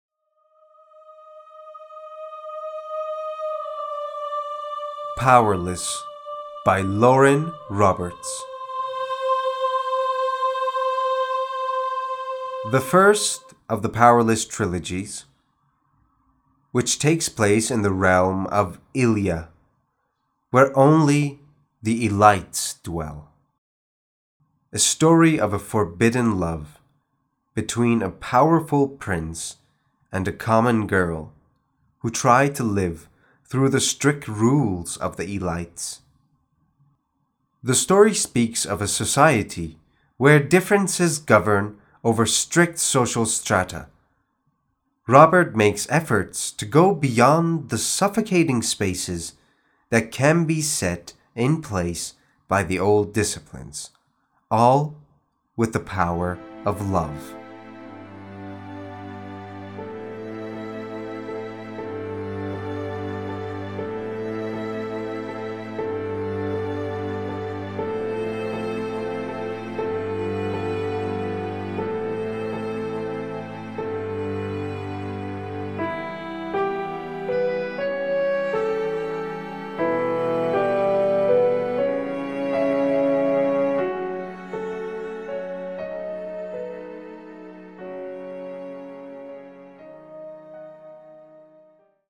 معرفی صوتی کتاب Powerless